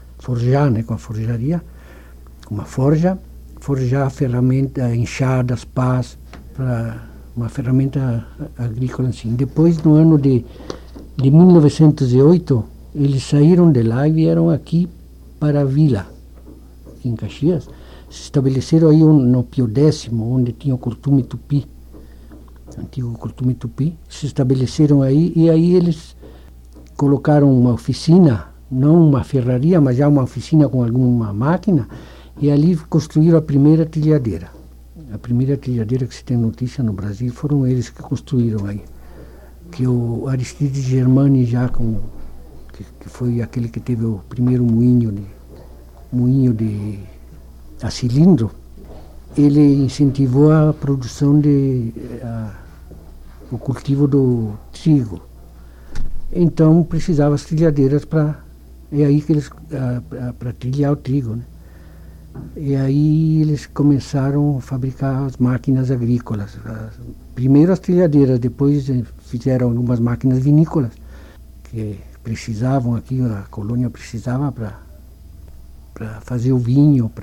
Trecho da primeira entrevista